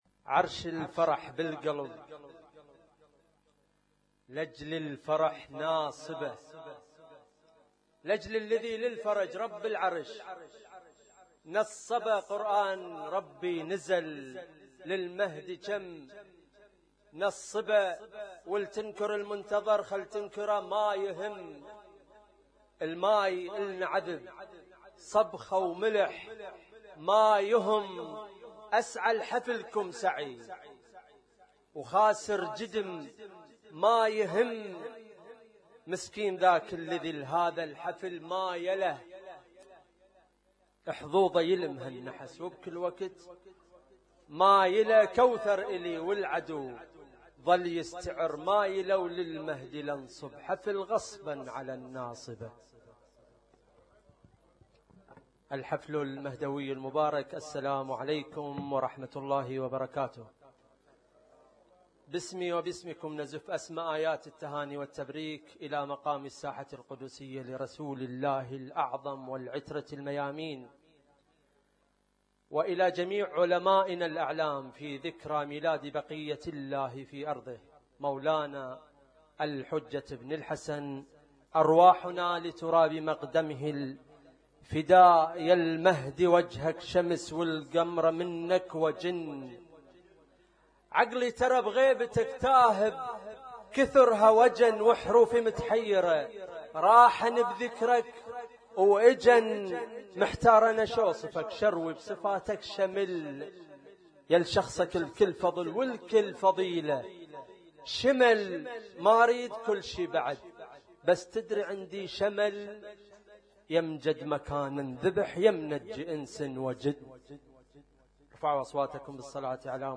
اسم التصنيف: المـكتبة الصــوتيه >> المواليد >> المواليد 1438